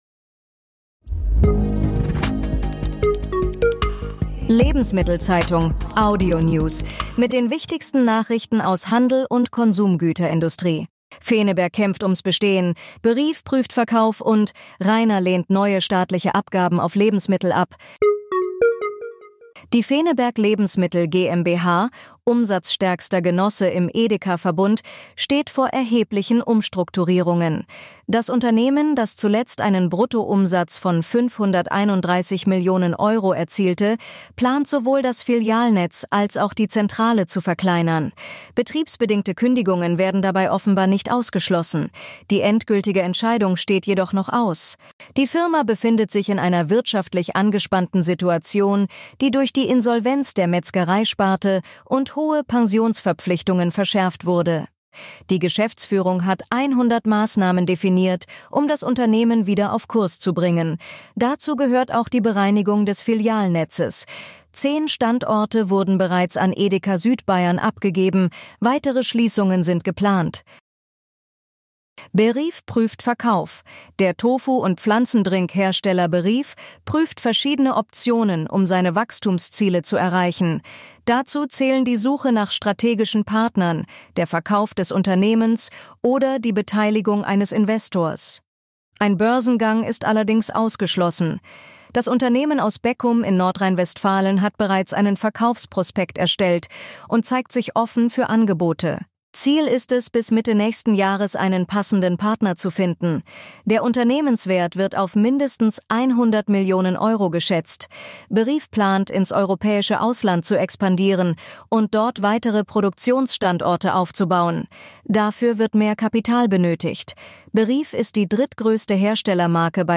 Hören, was wichtig ist: Wir fassen für Sie die spannendsten Nachrichten des Tages aus Handel und Konsumgüterindustrie zusammen. Von Montag bis Freitag, kompakt und prägnant.
Die wichtigsten Nachrichten aus Handel und Konsumgüterwirtschaft zum Hören